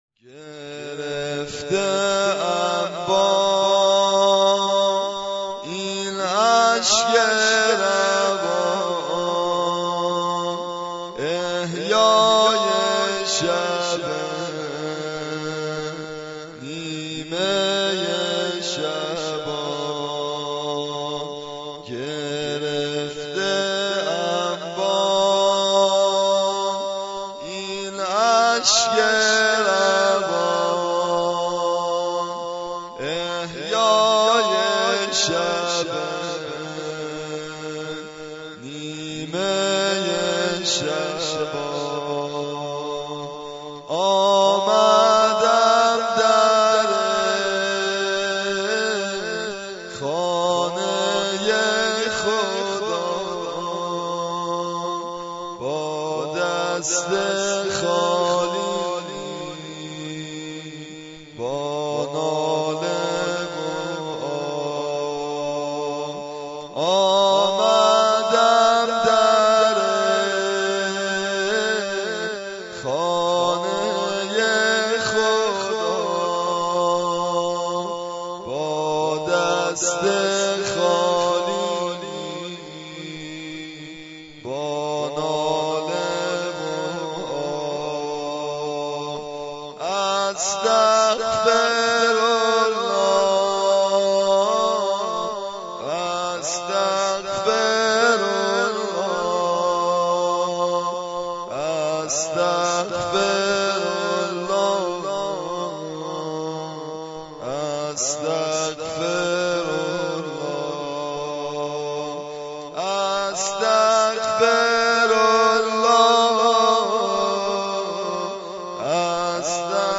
زمزمه برای احیای شب نیمه شعبان -( گرفته ام با ، این اشک روان ، احیای شب ِ، نیمه ی شعبان )